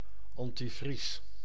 Ääntäminen
Synonyymit antigivre Ääntäminen France: IPA: [ɑ̃tiʒɛl] Haettu sana löytyi näillä lähdekielillä: ranska Käännös Ääninäyte Substantiivit 1. antivries Suku: m .